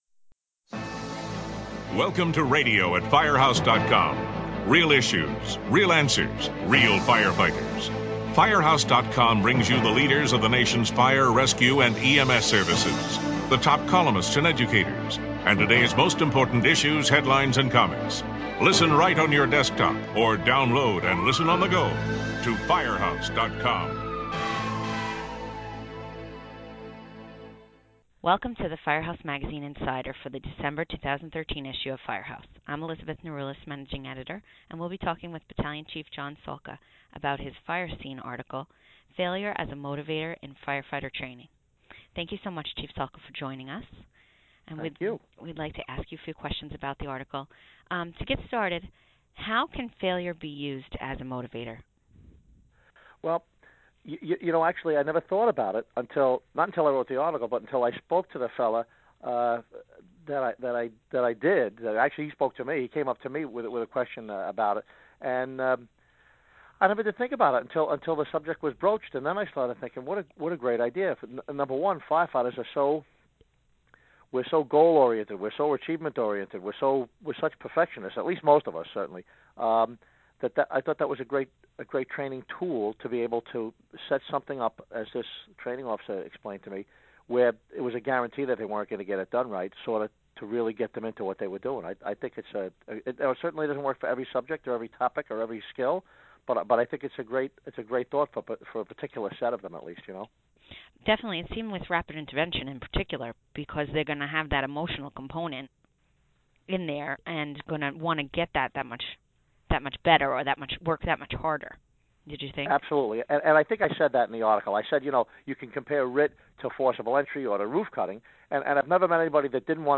Listen as the two discuss setting up a training event to promote failure in order to motivate crew members